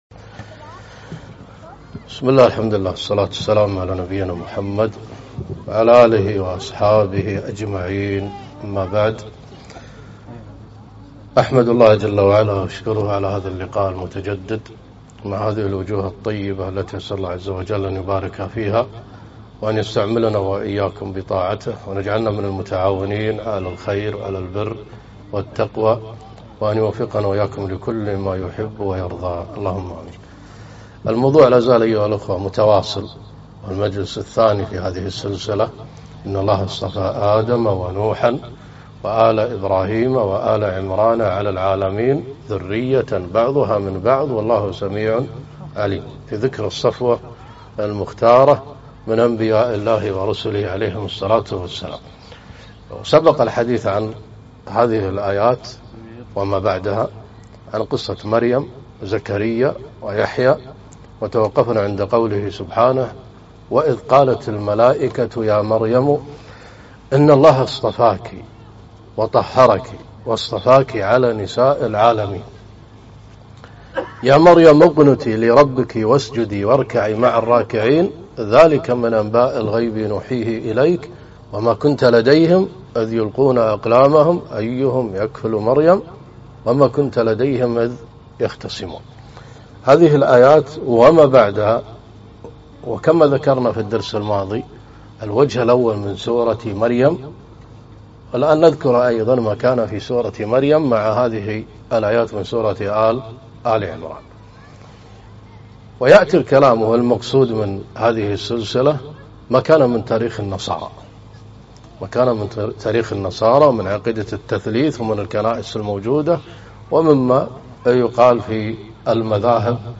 محاضرة+(ان+الله+اصطفى)+(١)_out.mp3